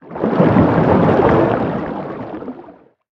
Sfx_creature_chelicerate_swim_slow_04.ogg